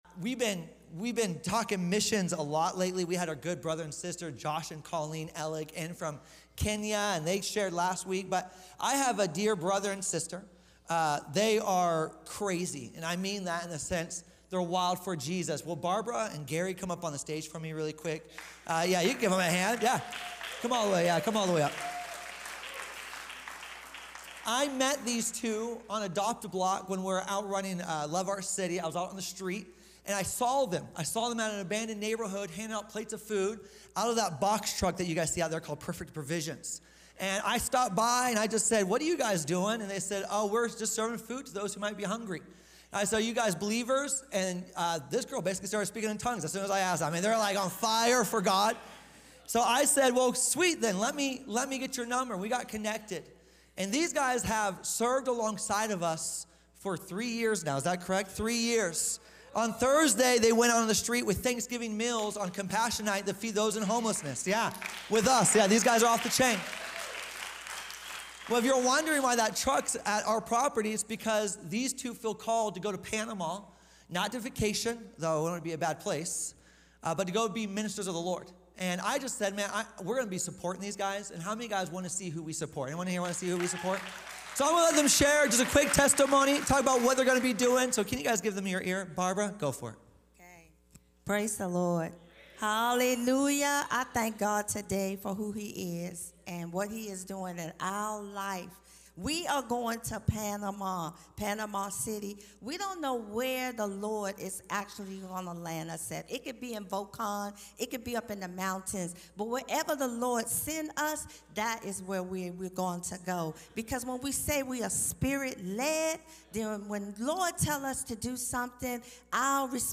Sunday Morning Live Stream